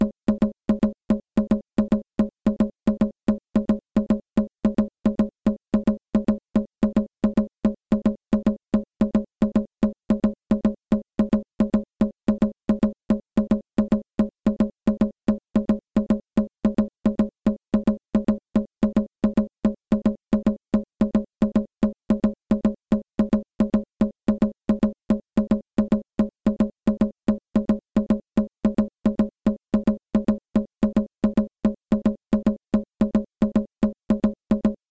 Chacun des 3 tambours porte un nom : tambú radá, maman ou dahomé pour le plus grave, sugó, segon ou second leguedé pour le médium et leguedé pour le plus aigu au son sec qui rappelle celui d'un idiophone en bois.
Le rythme Masú est binaire. Son tempo est modéré à rapide.
Très cadencée, la rythmique donne une sensation de continuité et de régularité.
Partie de leguedé